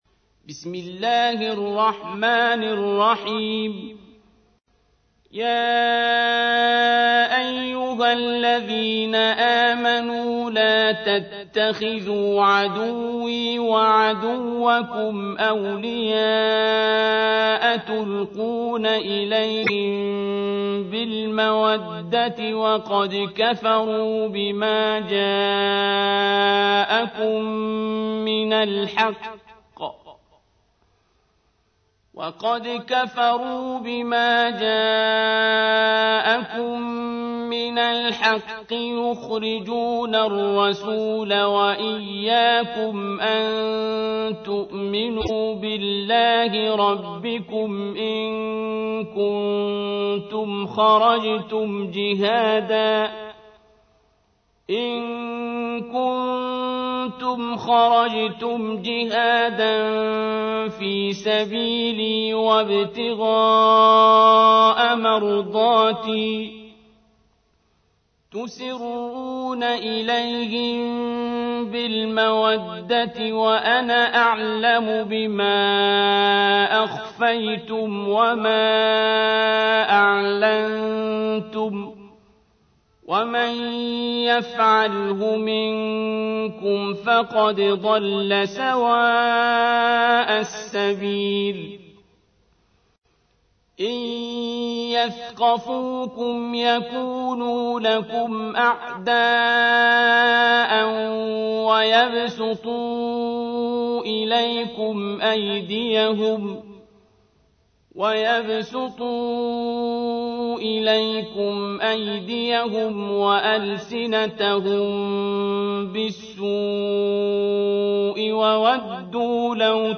تحميل : 60. سورة الممتحنة / القارئ عبد الباسط عبد الصمد / القرآن الكريم / موقع يا حسين